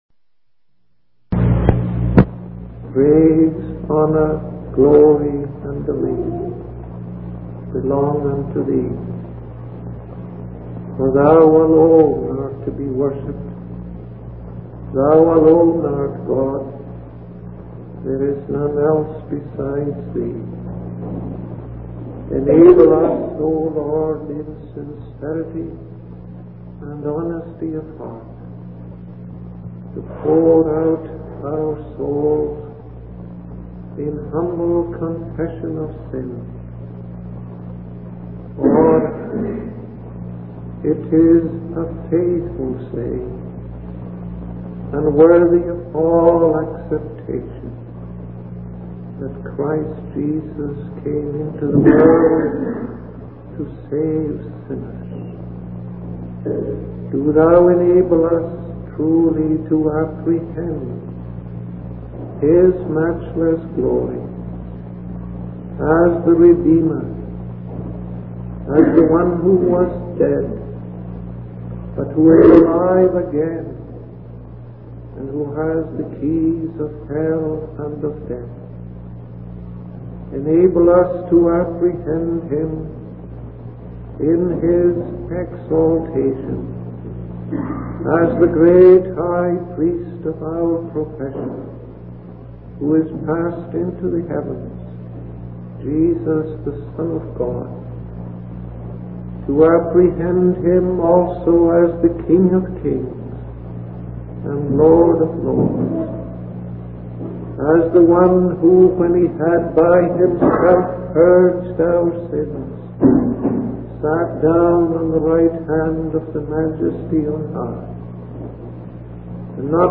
In this sermon, the preacher emphasizes the importance of Christ being formed in each believer, leading to the hope of glory.